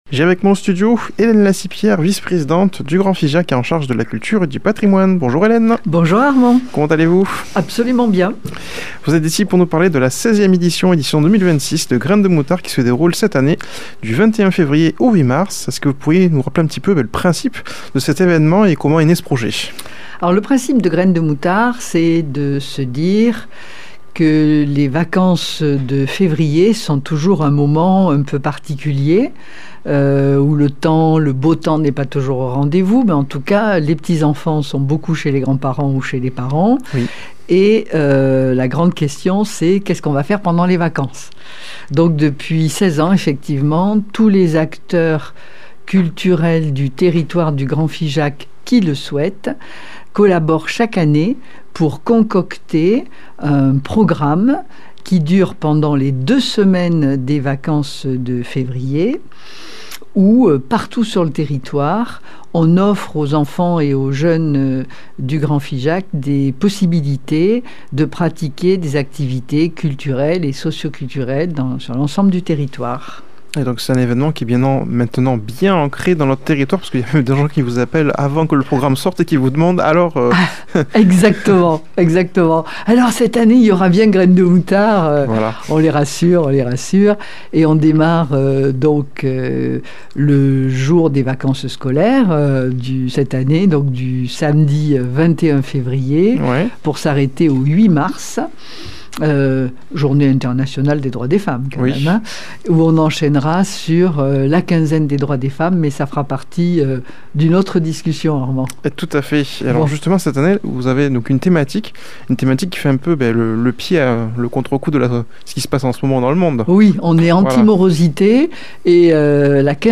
a comme invitée au studio Hélène Lacipière vice présidente du Grand Figeac et en charge de la culture et du patrimoine. Elle vient évoquer l'édition 2026 de la quinzaine culturelle à énergie positive Graines de Moutards qui se déroulera du Samedi 21 Février au Dimanche 8 Mars dans le Grand Figeac